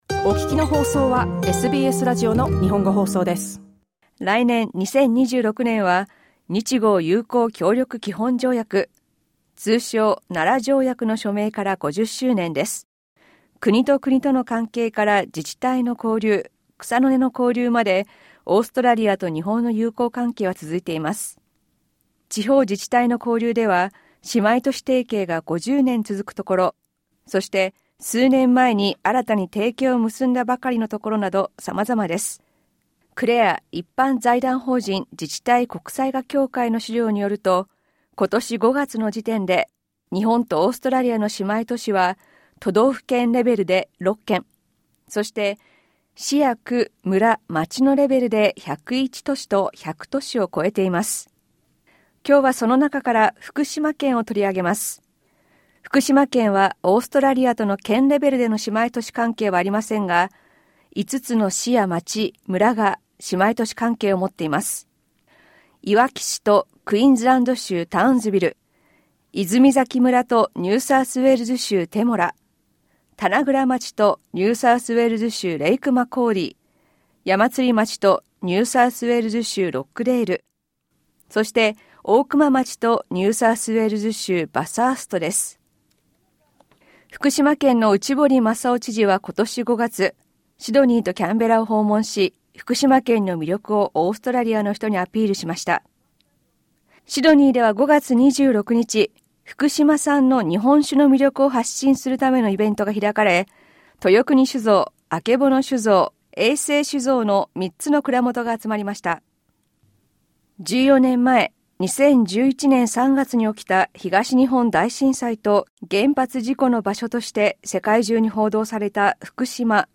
A promotional event for Fukushima-produced sake held at a restaurant in Sydney in May 2025. Masao Uchibori, Governor of Fukushima Prefecture, gave a speech at the event.